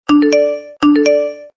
手机信息提示音.MP3